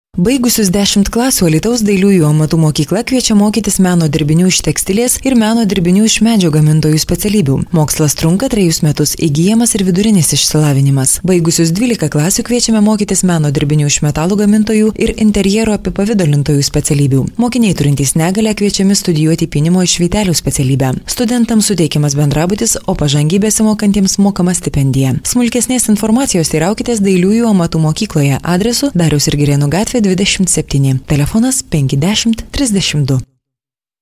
Sprecherin, Werbesprecherin
Female